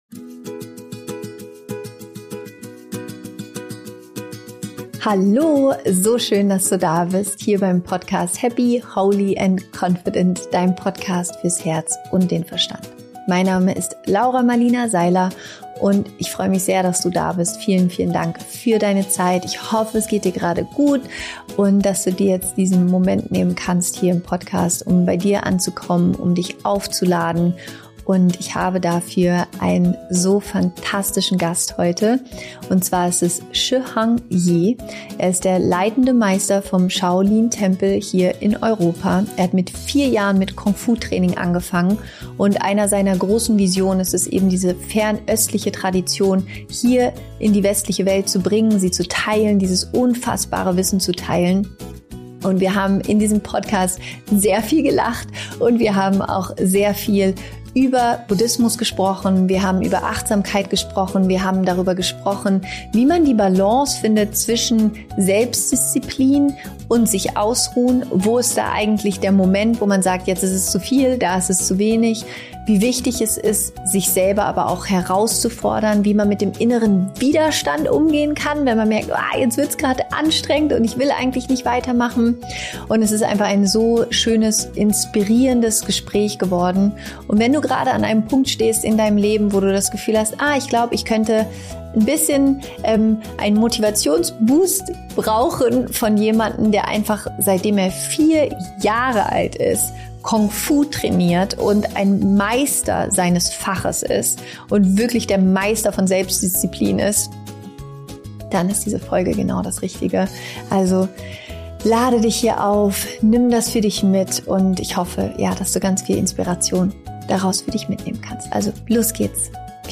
Wie du die Grenzen deines Geistes überwindest und über dich hinauswächst – Interview mit Shaolin-Meister Shi Heng Yi
In meiner neuen Podcastfolge habe ich den Shaolin-Meister Shi Heng Yi zu Gast.